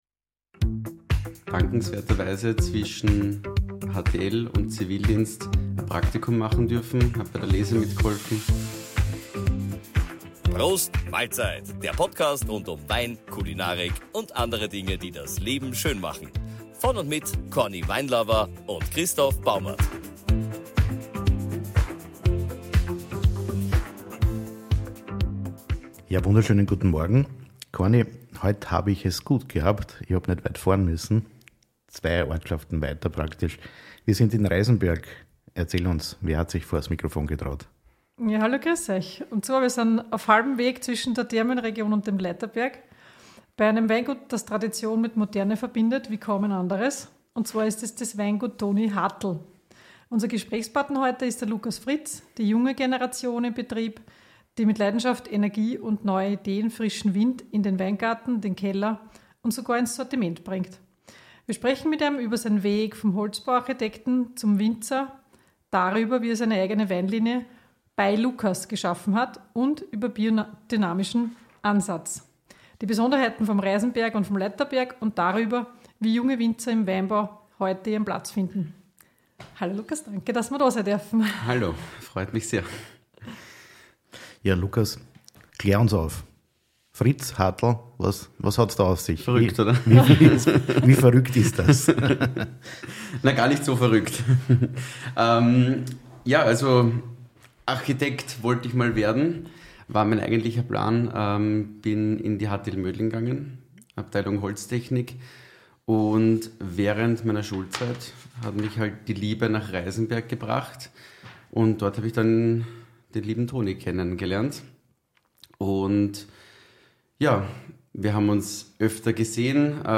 neue Ideen im Weingut Toni Hartl“ In dieser Episode von Prost Mahlzeit sind wir in Reisenberg zu Gast – auf halbem Weg zwischen Thermenregion und Leithaberg – beim Weingut Toni Hartl, einem Betrieb, der Tradition und Moderne auf besonders spannende Weise verbindet.